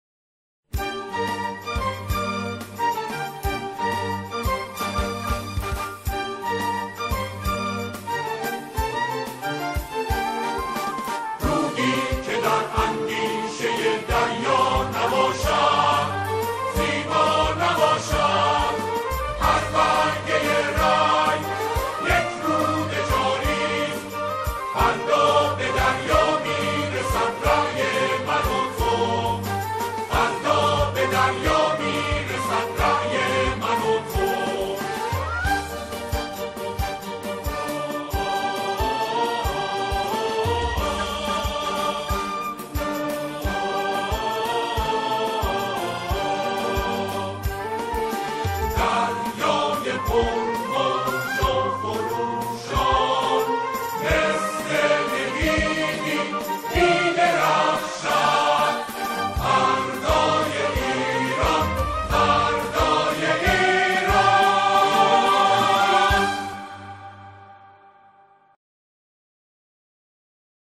سرود کوتاه